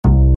bass_kontrabas.mp3